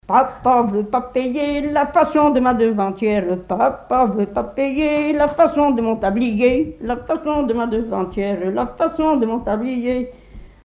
Chants brefs - A danser
danse : branle : courante, maraîchine
Chansons traditionnelles et populaires
Pièce musicale inédite